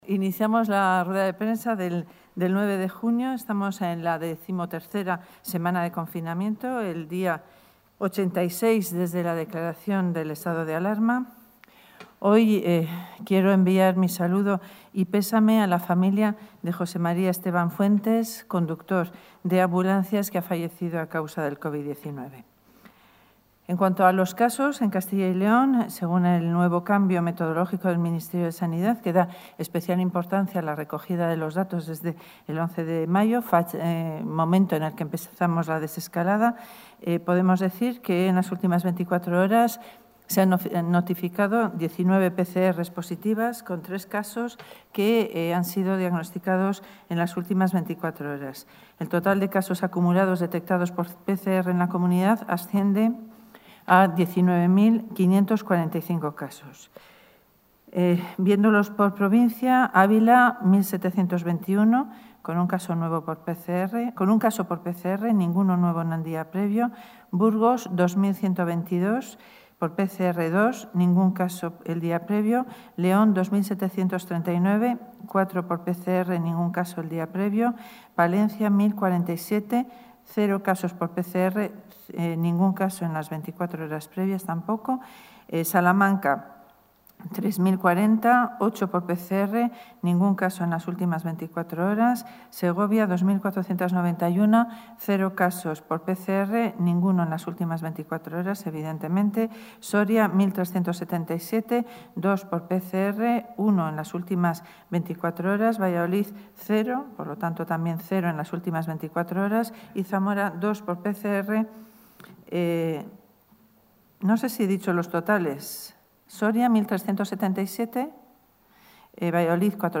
Material audiovisual de la rueda de prensa de la consejera de Sanidad para informar de la situación del COVID-19 en la Comunidad | Comunicación | Junta de Castilla y León
La consejera de Sanidad, Verónica Casado, ha comparecido hoy en rueda de prensa para informar de la situación en la Comunidad en relación al COVID-19.